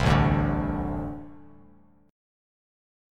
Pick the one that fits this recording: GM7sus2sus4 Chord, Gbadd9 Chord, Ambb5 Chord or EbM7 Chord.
Ambb5 Chord